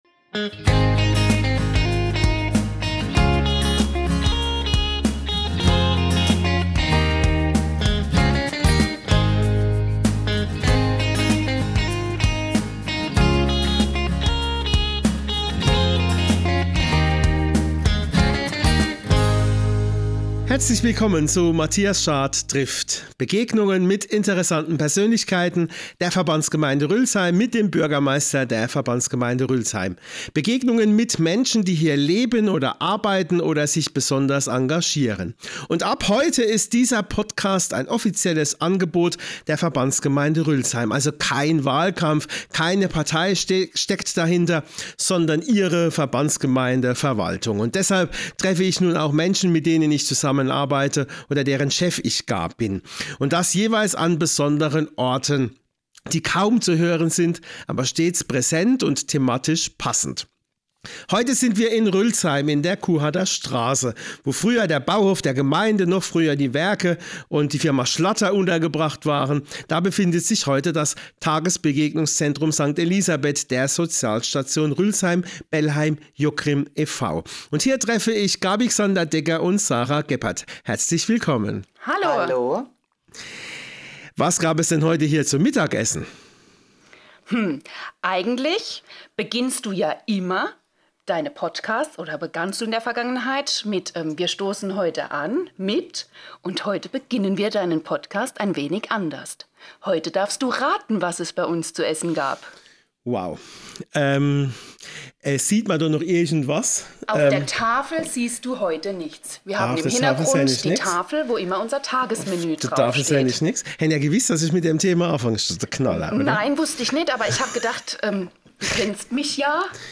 Im Tagesbegegnungszentrum St. Elisabeth sprechen wir über die vielfältige Arbeit der Sozialstation sowie die Situation in der Pflege. Und was eine Zwitscherbox mit alledem zu tun hat, das erfahren Sie in dieser Folge.